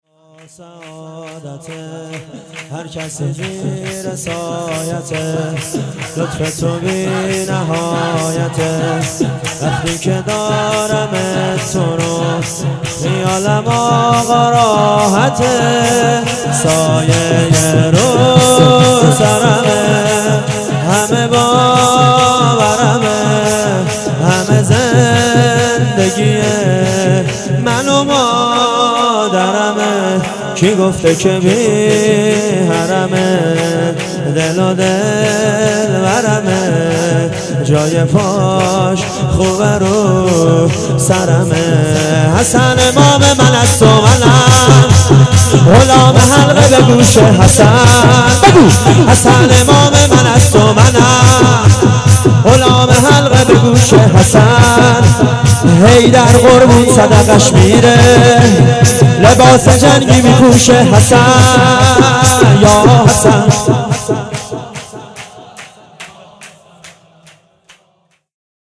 شور
جشن ولادت حضرت معصومه سلام الله علیها